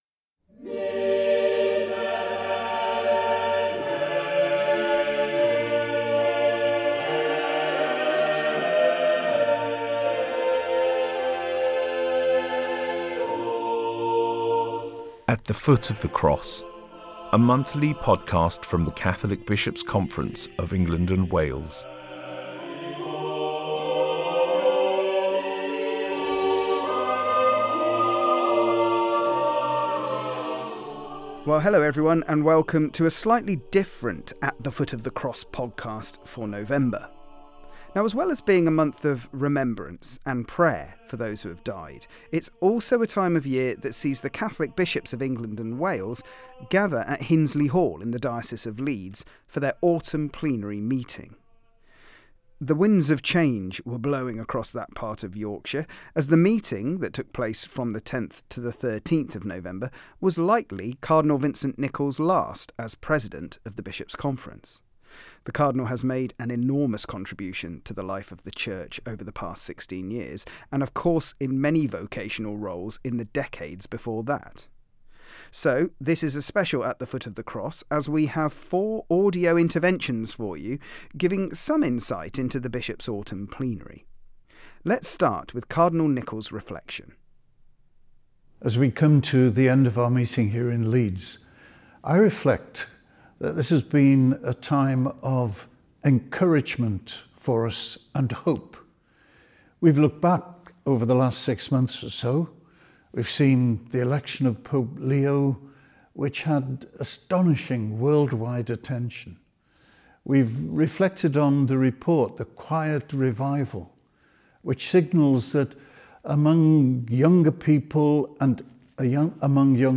As well as being a month of remembrance and prayer for those who have died, both on our parish November lists and in the World Wars and other conflicts, it’s also a time when the Catholic Bishops of England and Wales gather at Hinsley Hall in the Diocese of Leeds for their autumn plenary meeting. This podcast consists of four episcopal audio interventions giving a flavour of the Bishops’ meeting.